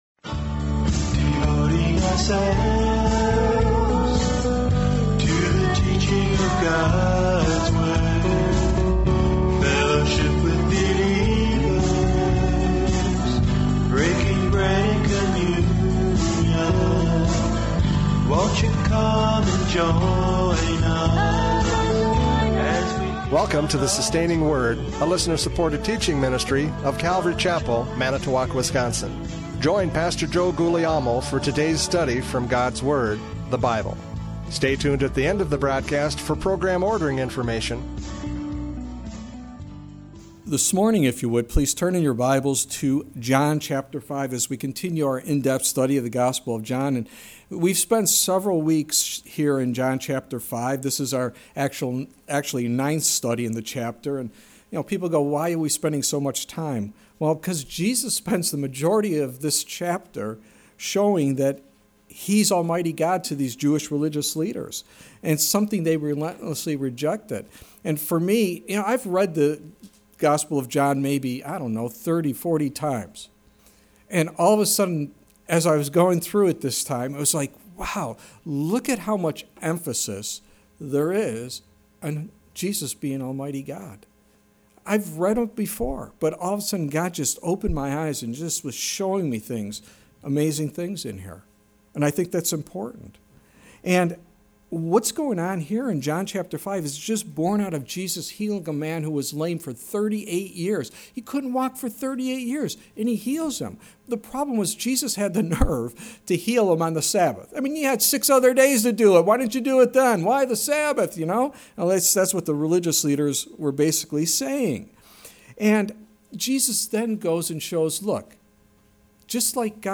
John 5:39-47 Service Type: Radio Programs « John 5:37-38 Testimony of the Father!